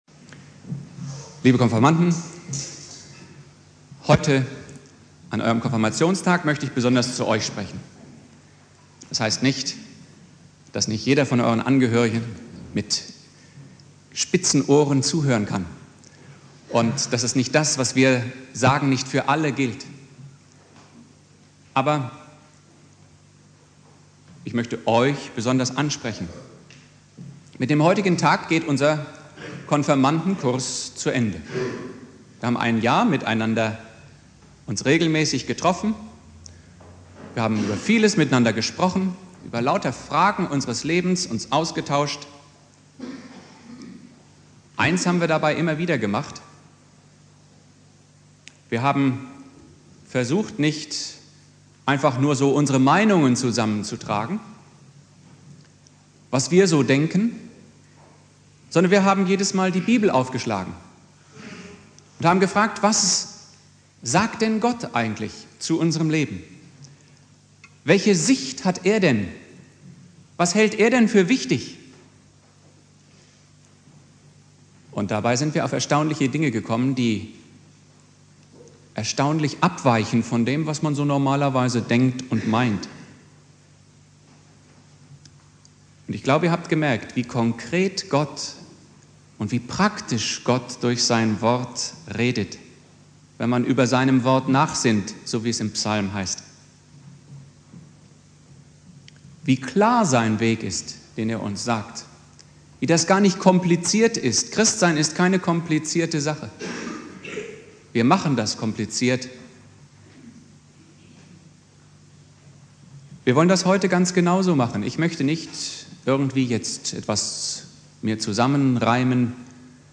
Predigt
Thema: Konfirmation Hausen